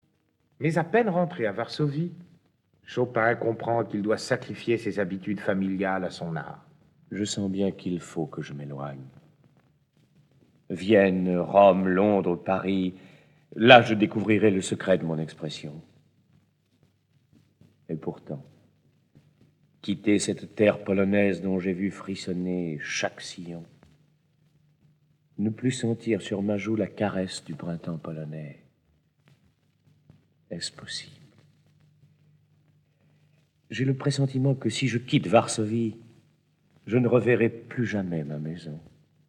Get £0.77 by recommending this book 🛈 Voici une biographie musicale de Frédéric Chopin : sa vie et son oeuvre sont racontées, et des pauses musicales présentent des extraits de plus belles oeuvres du compositeur. Dès l'âge de 7 ans, Frédéric Chopin compose ses premières oeuvres.
Avec Jean Desailly, Simone Valère, et 1 autre comédien